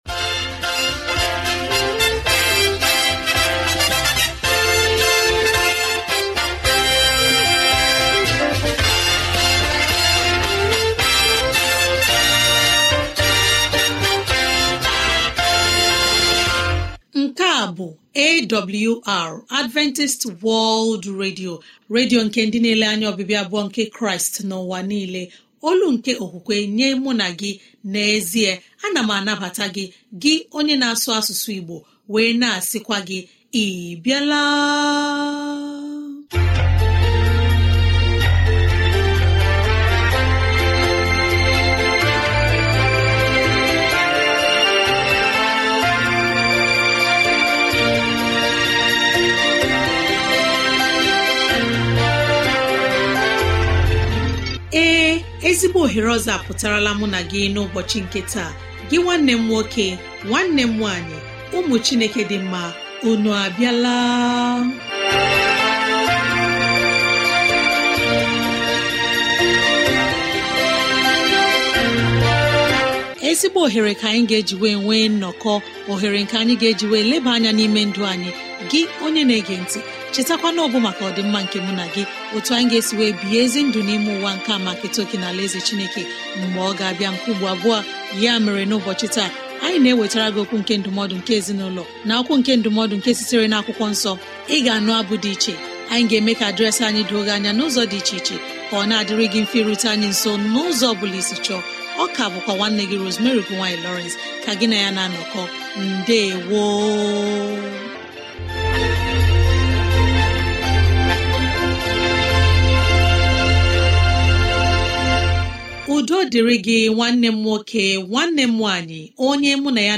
Daily Ibo radio program by Adventist World Radio